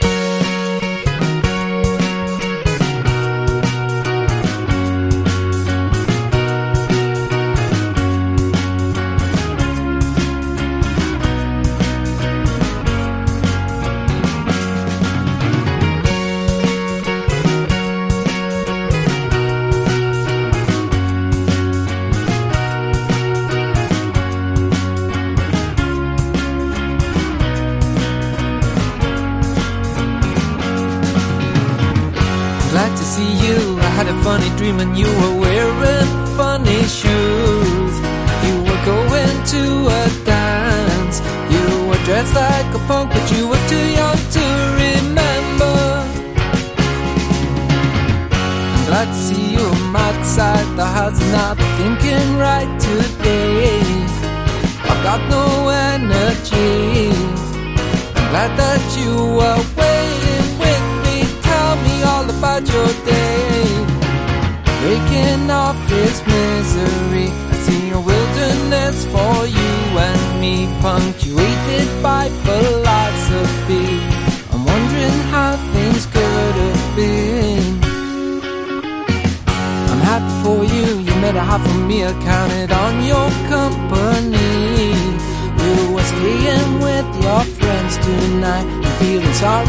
楽曲自体もTHIN LIZZYを彷彿させる泣きメロがたまりません。